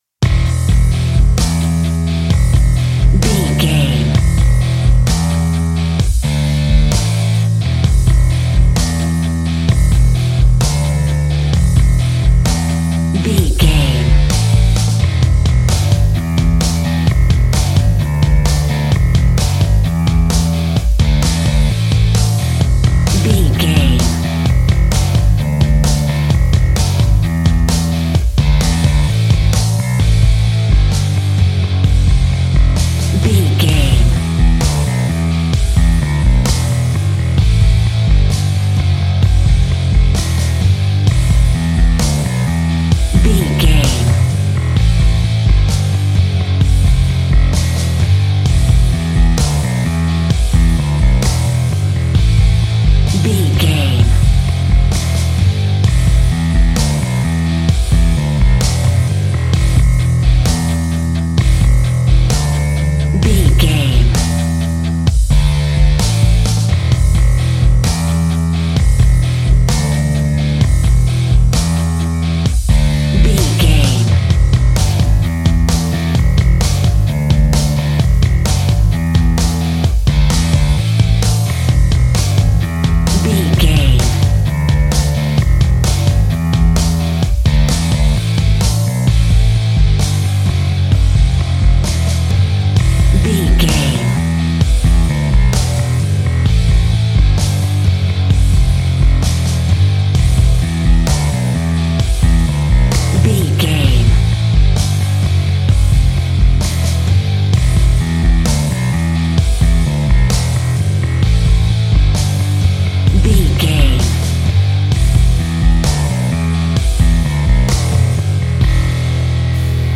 Epic / Action
Fast paced
Aeolian/Minor
hard rock
blues rock
rock guitars
Rock Bass
Rock Drums
heavy drums
distorted guitars
hammond organ